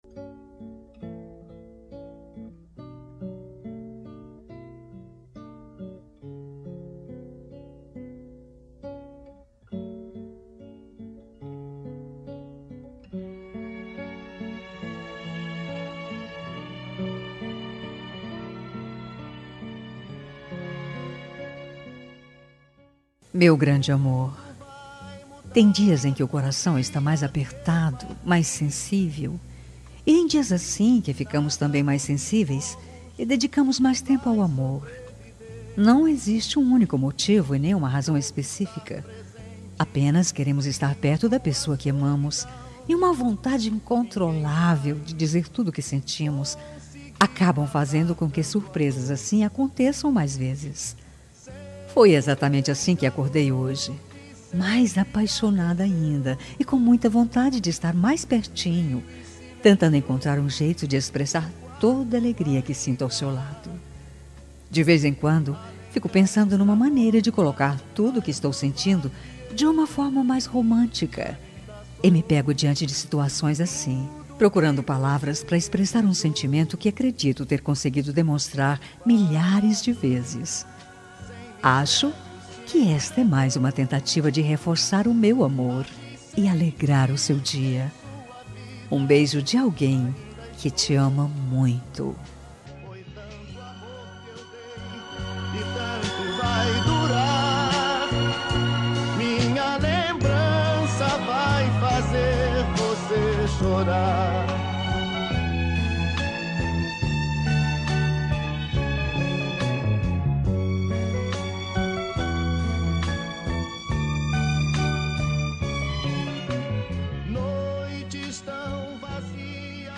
Telemensagem Romântica – Voz Feminina – Cód: 6461